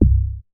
MoogAmigo C.WAV